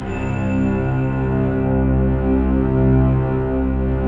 Index of /90_sSampleCDs/Optical Media International - Sonic Images Library/SI1_BellStrings/SI1_SlowBellStrg
SI1 BELLS03R.wav